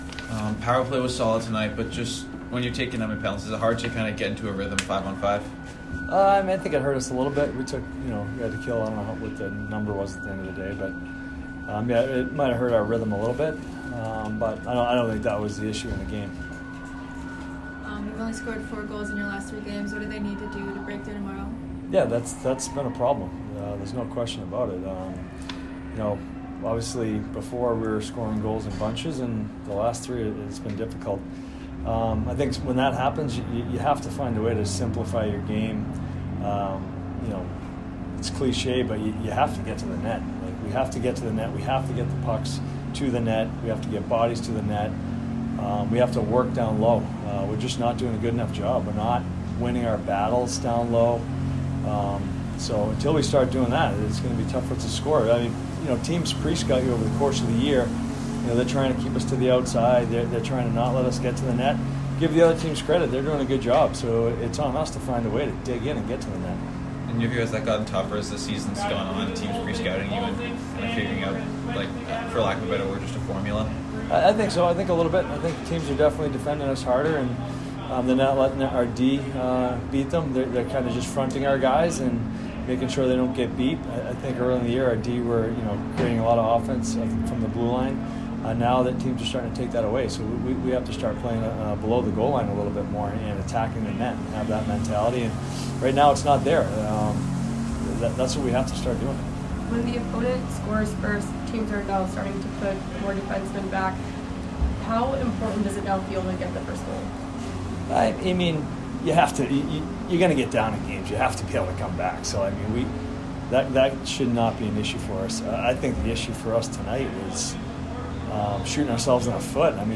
Merrimack Postgame Interview